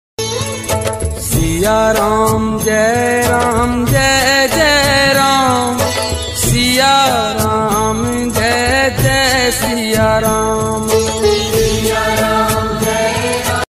Akhand Ram Dhun Ringtone.